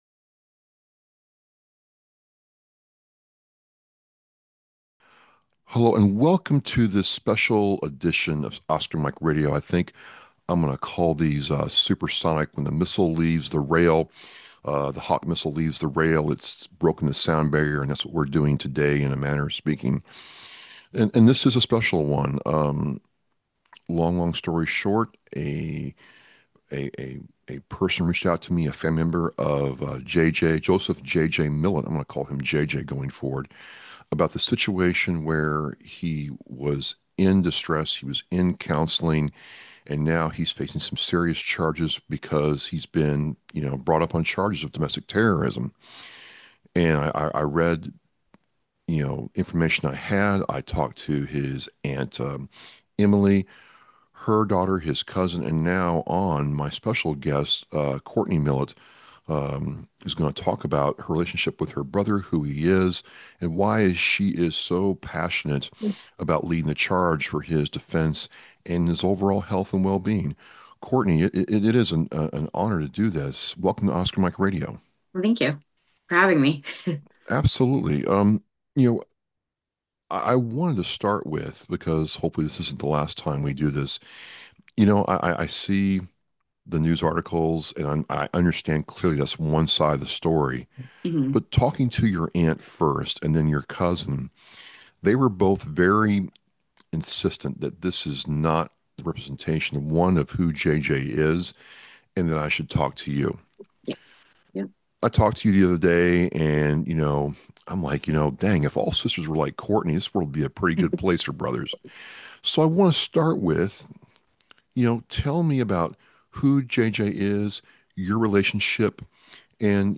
This episode of Oscar Mike Radio is one of the most personal and emotional conversations I’ve ever had.